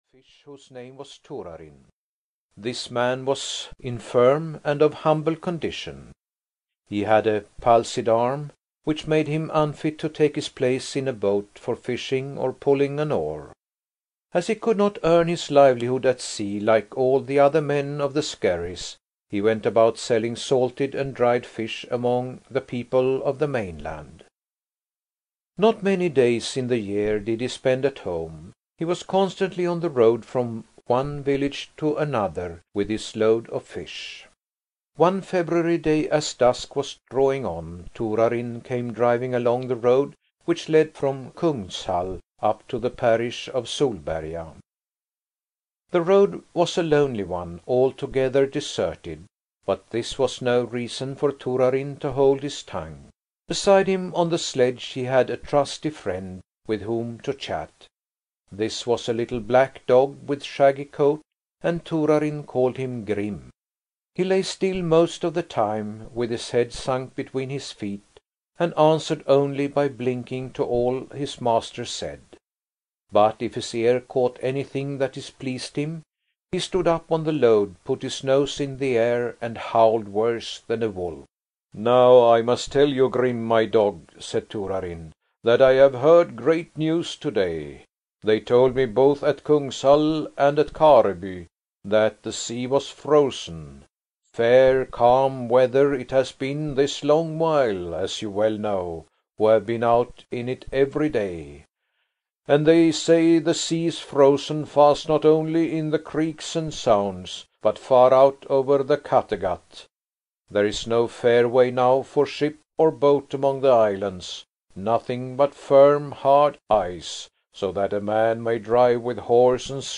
Audio knihaHerr Arne's Hoard (EN)
Ukázka z knihy